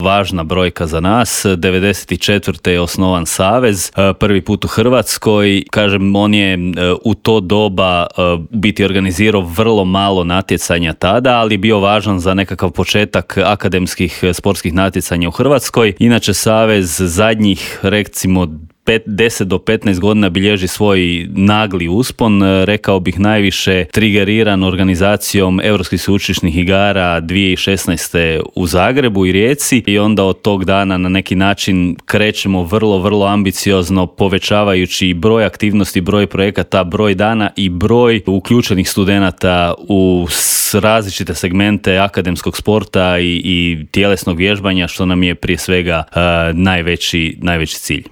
U intervjuu Media servisa